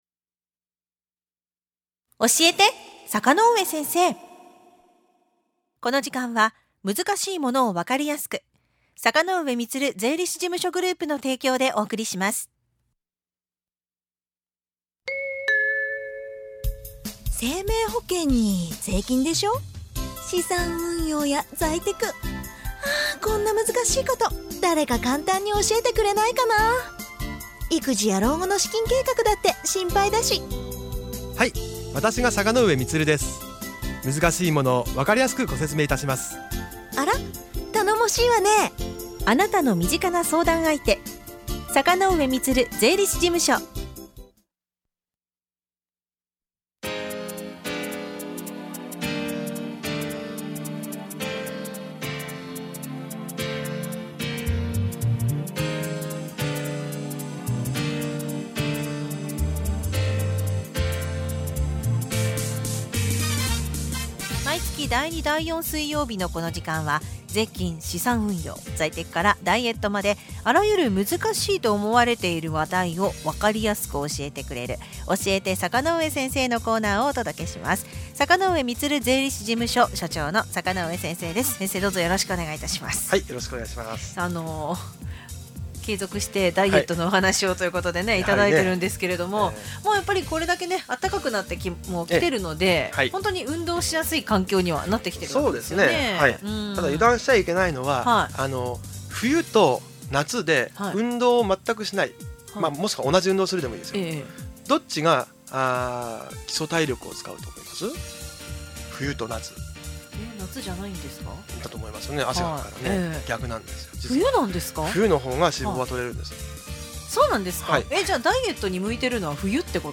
この音声は、5月25日のラジオたかおか放送内容です。